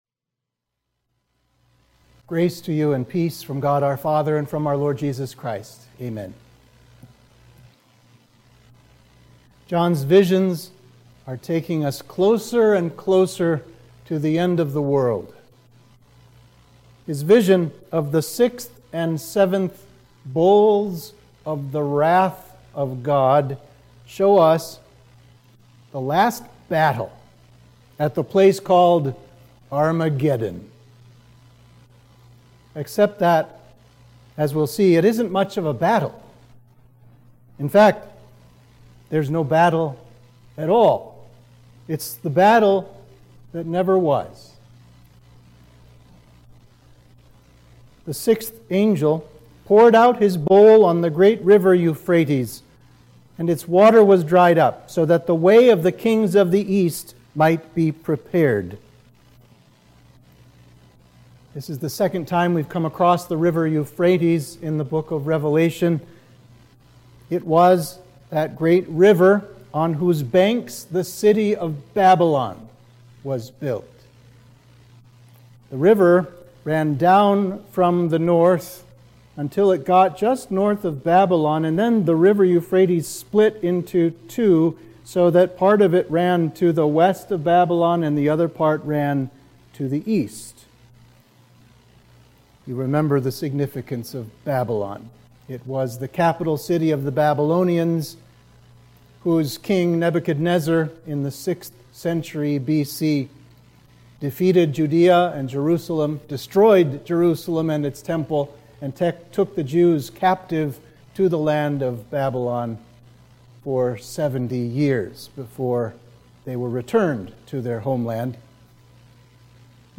Sermon for Midweek of Trinity 12